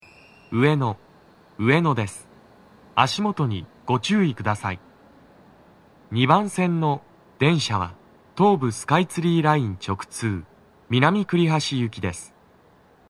スピーカー種類 TOA天井型
足元注意喚起放送が付帯されています乗降が多く、フルはそこまで粘らず録れます
男声